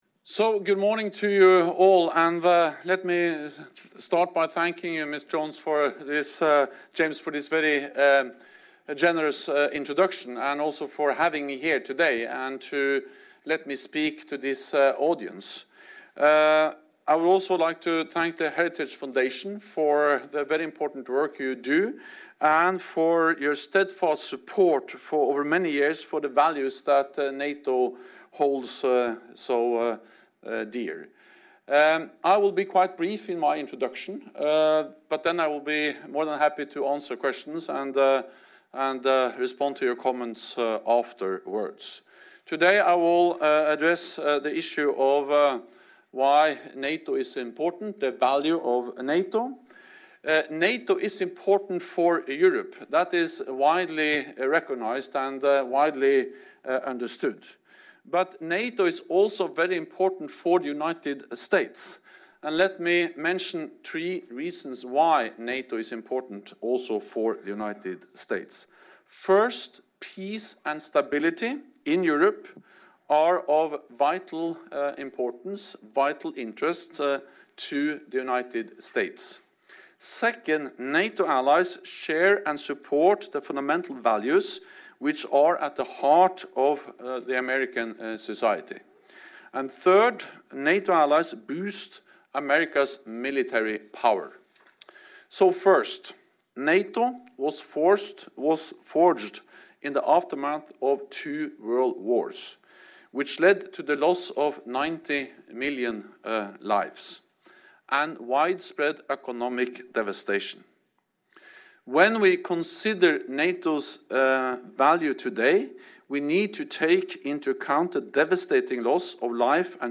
Remarks
by NATO Secretary General Jens Stoltenberg at the Heritage Foundation in Washington, DC